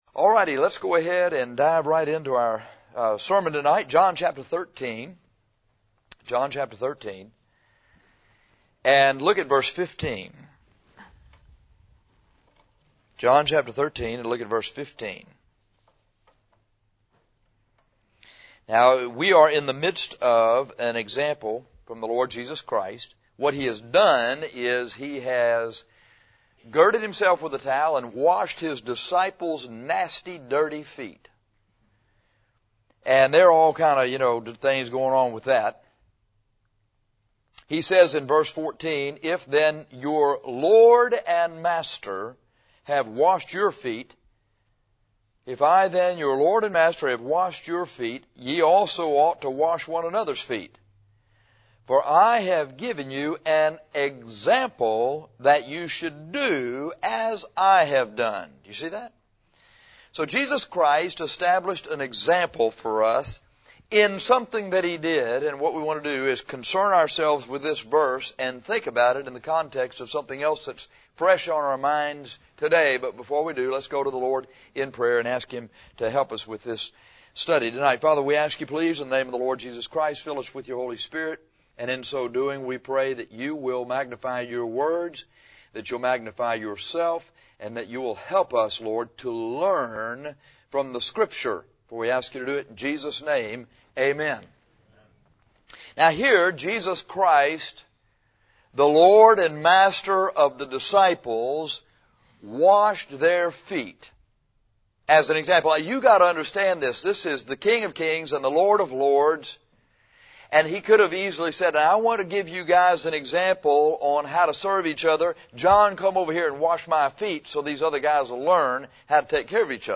This sermon is about following God's example of fatherhood.